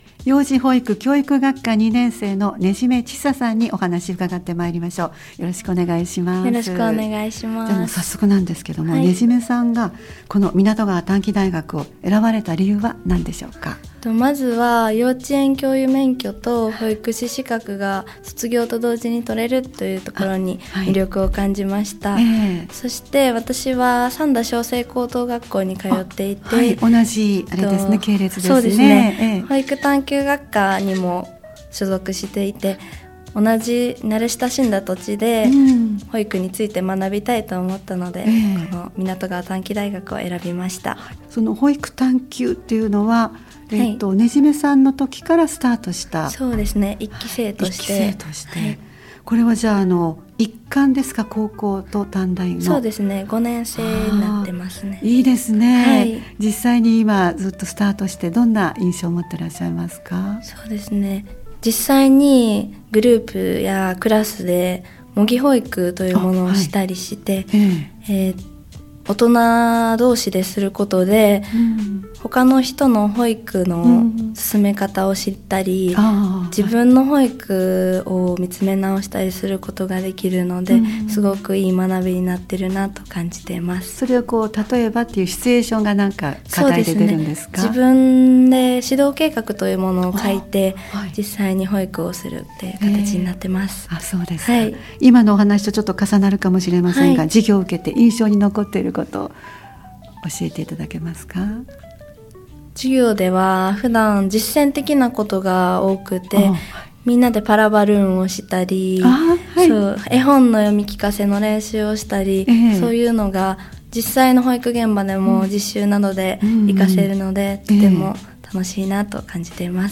【みなとっちラジオ！スペシャル】湊川短期大学　幼児教育保育学科の学生に聞きました！